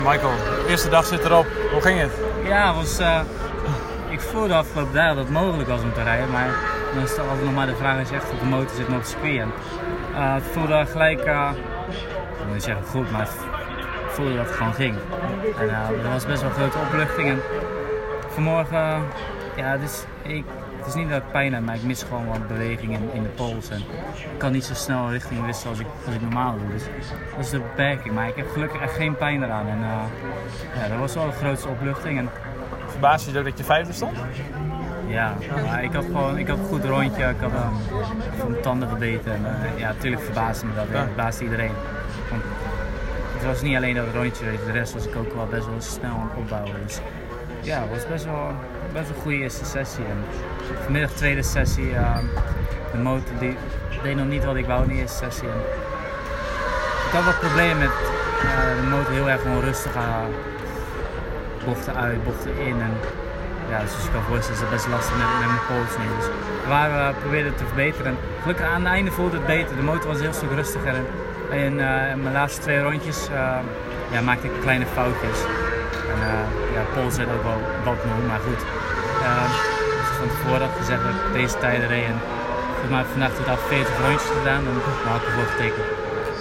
Direct na afloop van de tweede vrije training zochten we Van der Mark op en vroegen we hem om een eerste reactie.